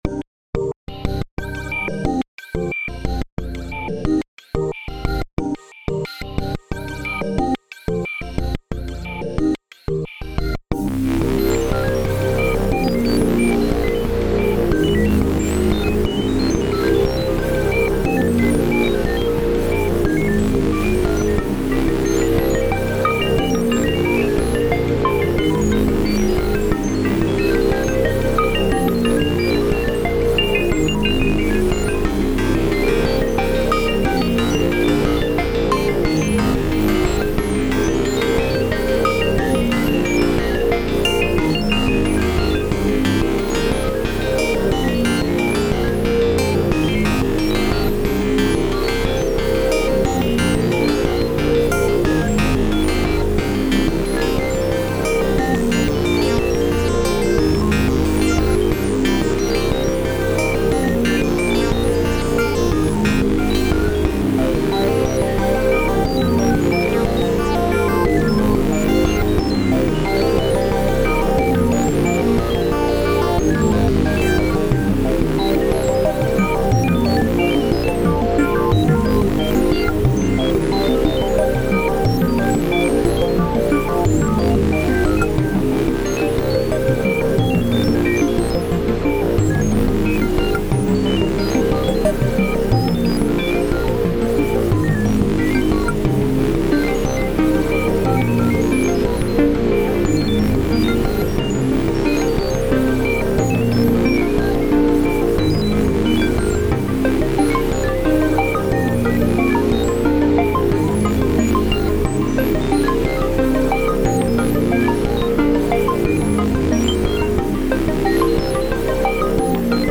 サイバー空間をイメージしたBGM。
タグ: ゲーム チュートリアル/解説 電子音楽 音少なめ/シンプル コメント: サイバー空間をイメージしたBGM。